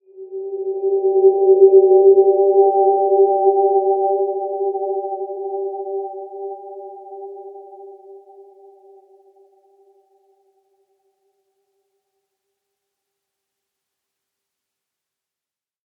Dreamy-Fifths-G4-p.wav